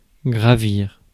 Ääntäminen
Ääntäminen US : IPA : [klaɪm]